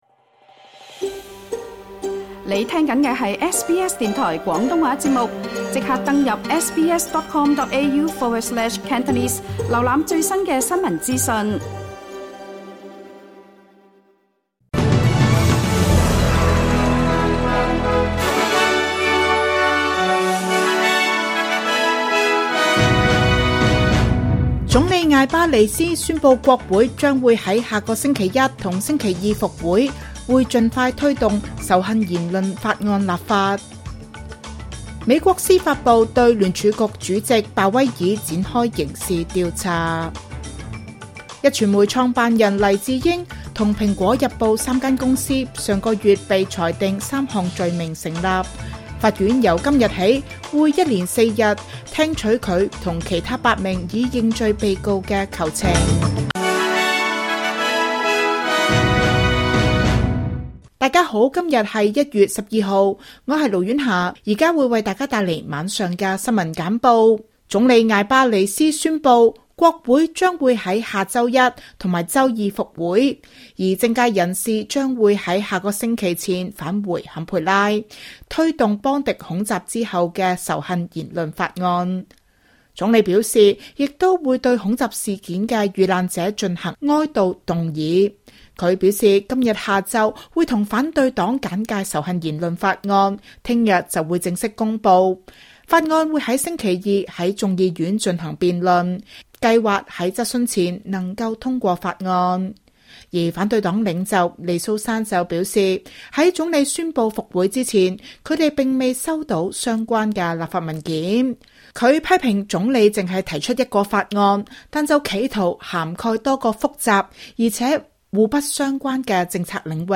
請收聽本台為大家準備的每日重點新聞簡報。
SBS 廣東話晚間新聞